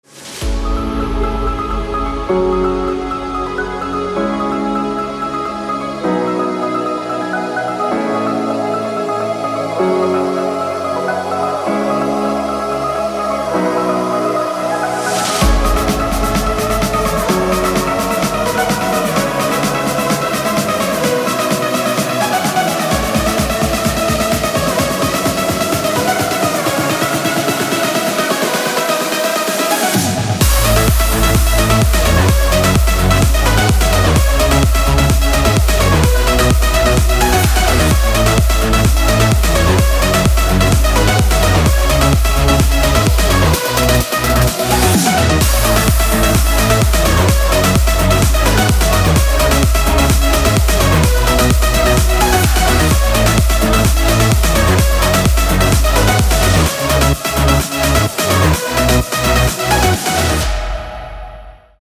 • Качество: 256, Stereo
dance
электронная музыка
без слов
клавишные
club